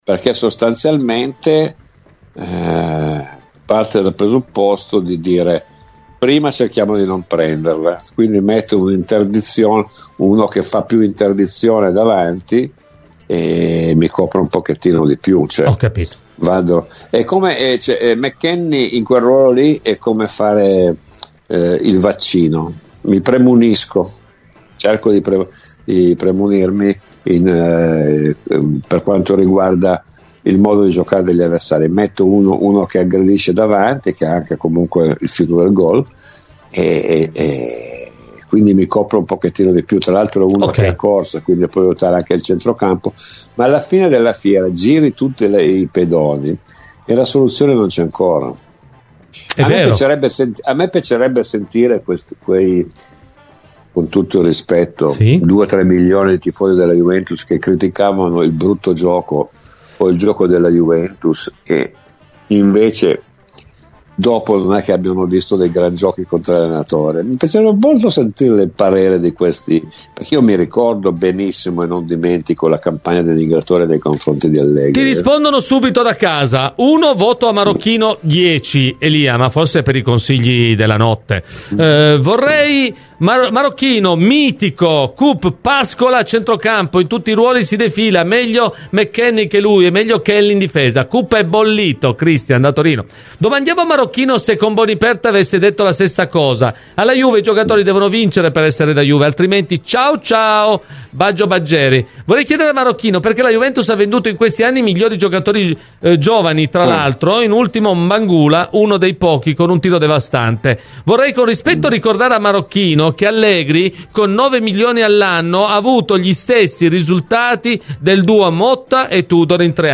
Nel corso di “Cose di Calcio” su Radio Bianconera, Domenico Marocchino ha commentato la prova del Franchi della Juventus senza nascondere il proprio disappunto: “Sono abbastanza deluso perchè capisco che in poco tempo puoi fare poco, ma una partita può anche essere letta in corso di svolgimento o comunque affrontata in un certo modo”.